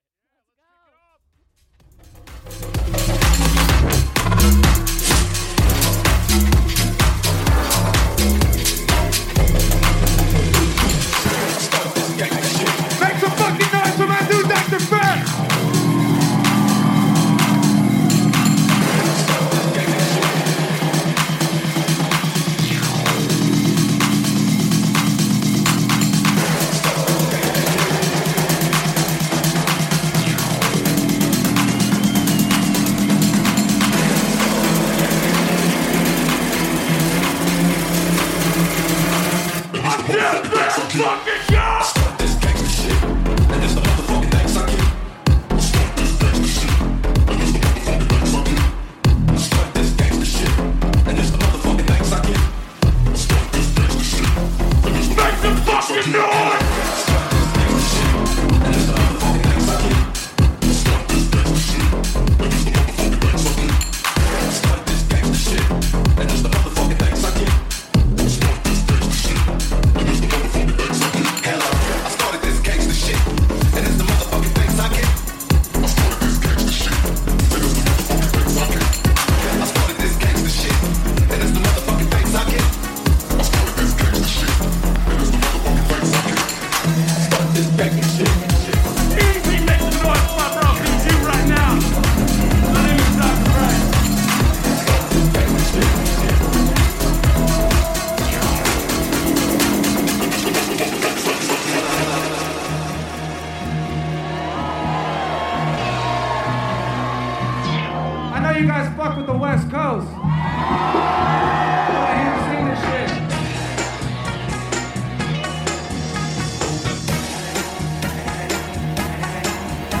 Also find other EDM Livesets, DJ Mixes and
Liveset/DJ mix